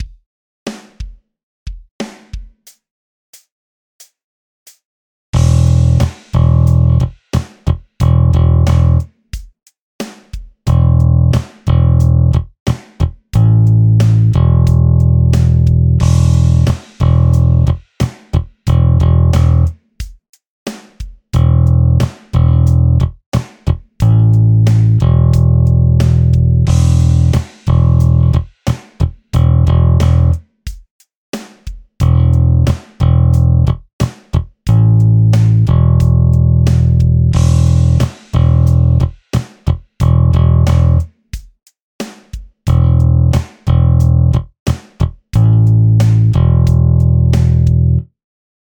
6．開放弦と長い音符／休符を使用したベース練習フレーズ７選！
7．長音符と長休符が含まれる練習フレーズ